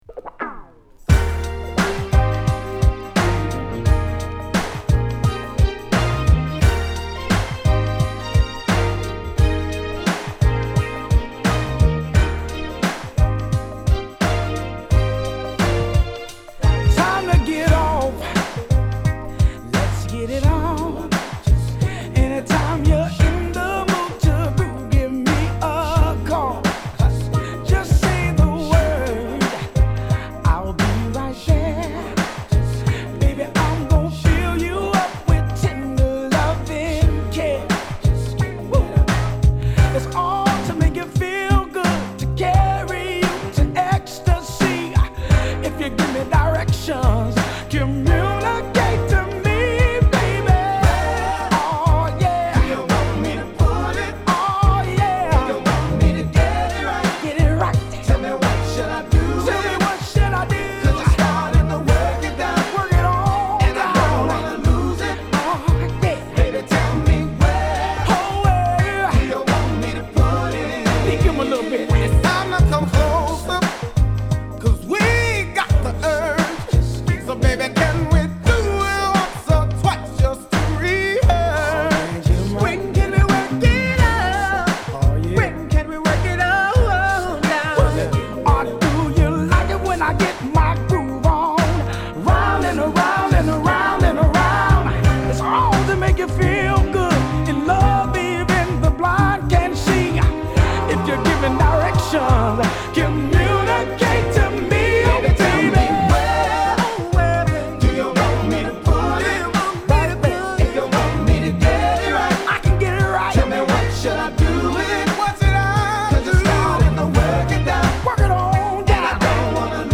オーセンティックなメロディにソウルフルなリードVo.と流石のコーラスワークの高品質R&B！
こちらもメロウで良い曲！